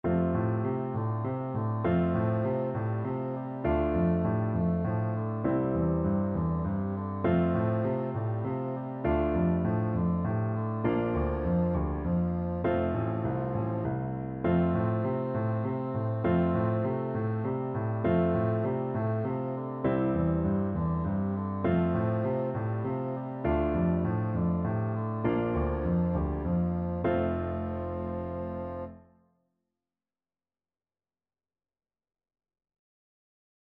Bassoon version
is a Korean folk song
3/4 (View more 3/4 Music)
Traditional (View more Traditional Bassoon Music)